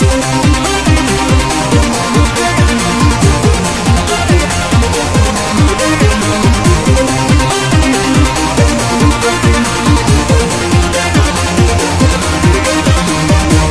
描述：电子（恍惚）循环。
TECHNO drumloop 法兰 俱乐部 低音 合成器 节拍 神志恍惚 140-BPM 电子 旋律 狂欢 扭曲 变形 坚硬 房子 舞蹈 序列